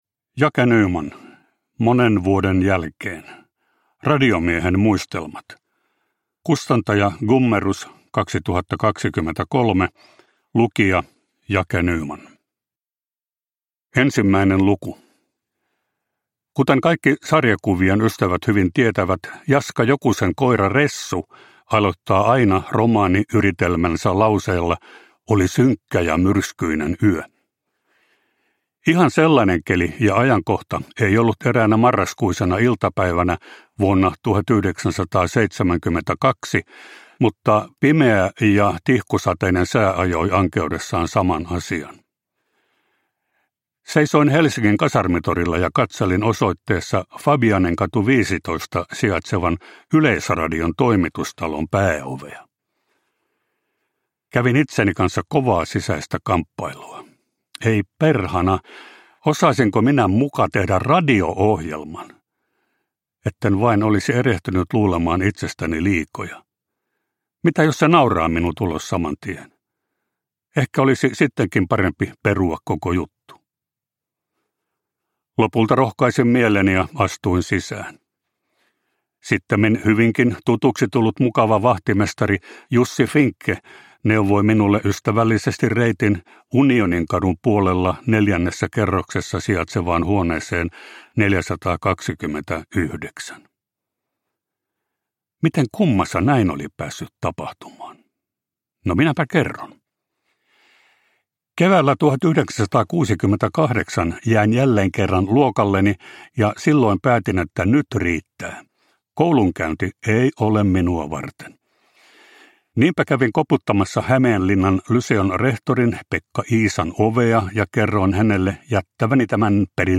Monen vuoden jälkeen (ljudbok) av Jake Nyman